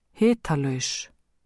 prononciation